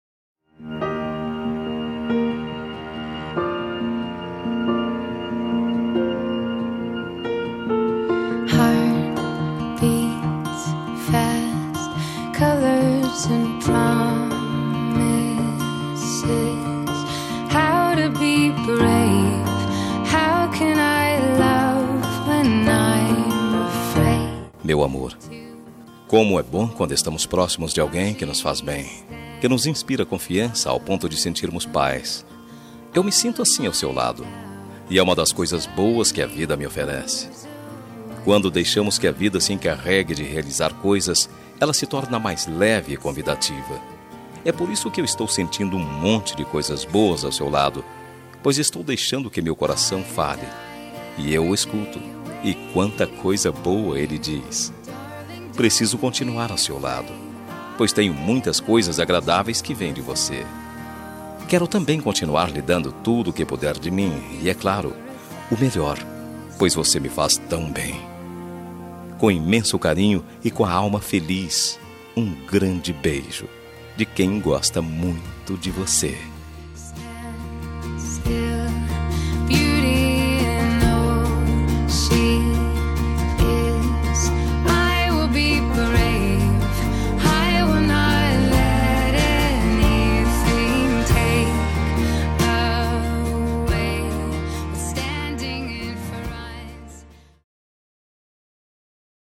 Telemensagem Amante – Voz Masculina – Cód: 5404